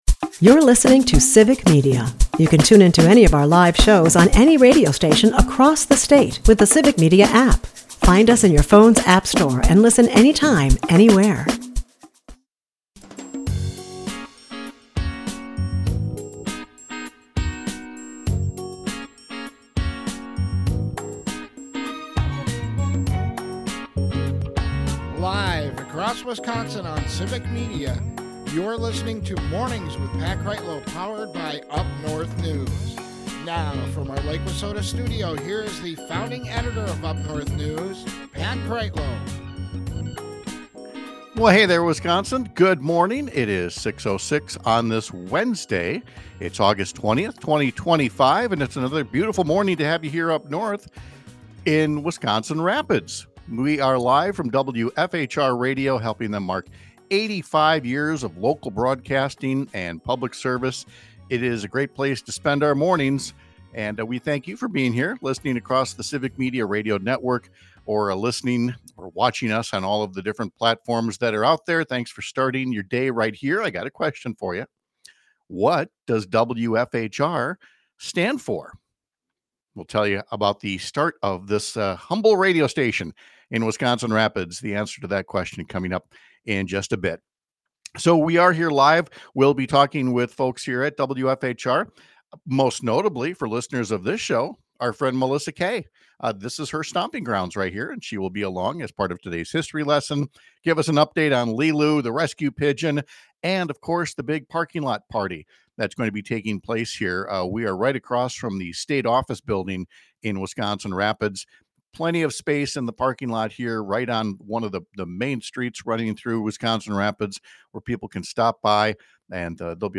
We are live in Wisconsin Rapids to help WFHR celebrate 85 years of local broadcasting. We’ll talk to members of the Civic Media family about the historic role the station has played over decades of community service.